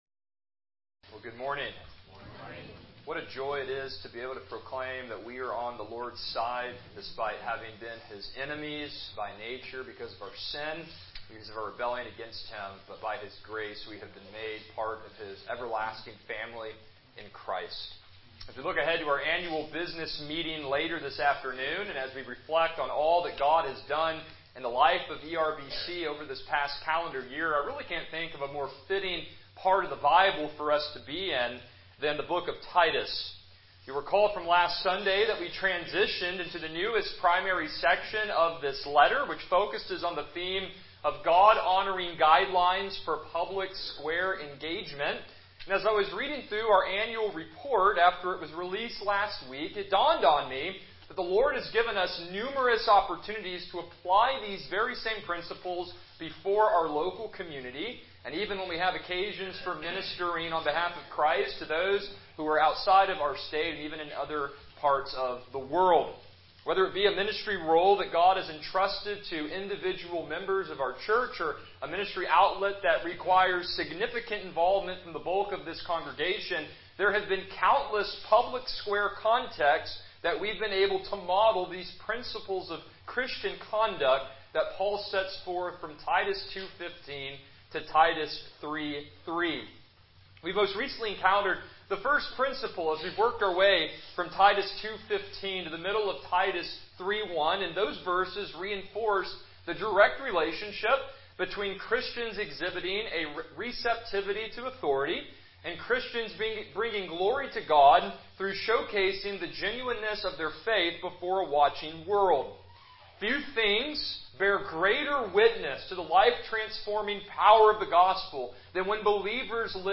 Passage: Titus 3:1-3 Service Type: Morning Worship